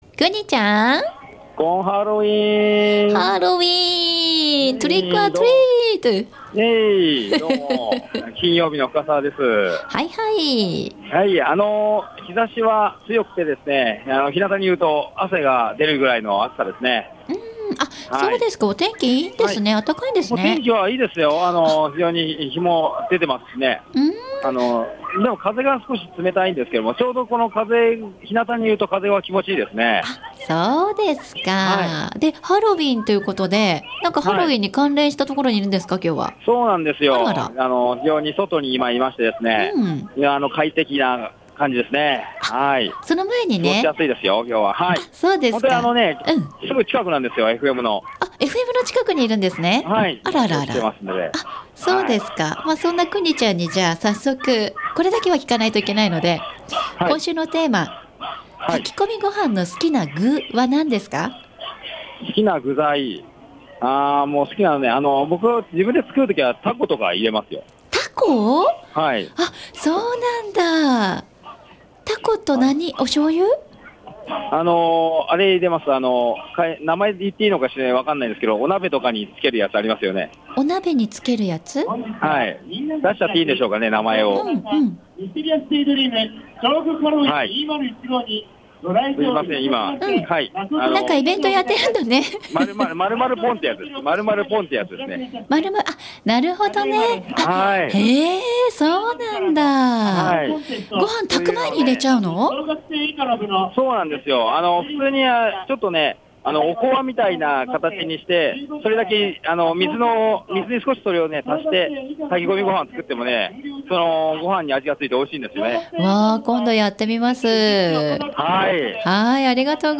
調布駅前南口広場での
たくさんに人で賑わってました。